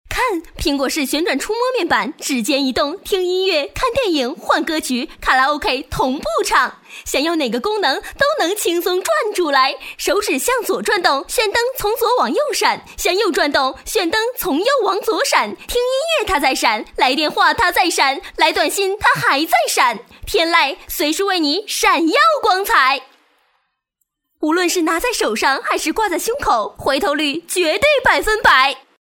专题片宣传片配音作品在线试听-优音配音网
女声配音